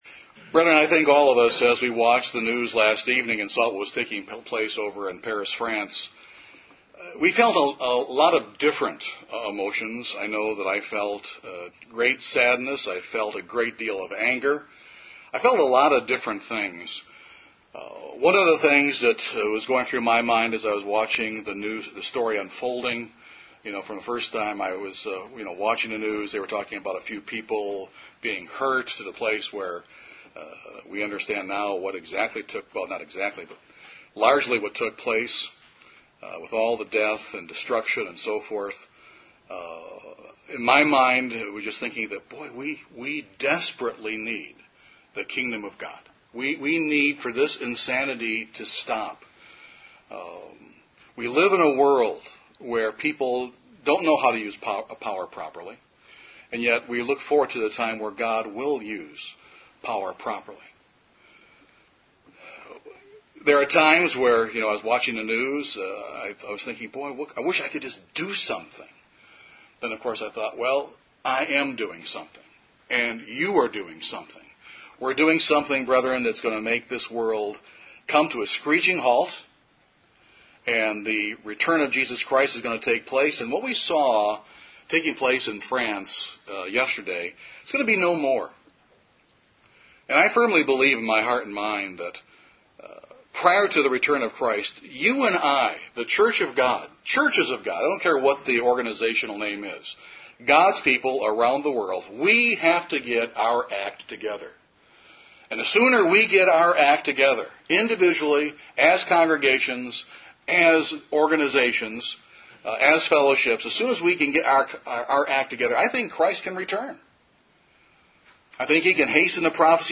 One of the greatest ways that God's power is seen is in the changed lives of Christians. This sermon discusses how the word of God is used to effect powerful change in the believer's life.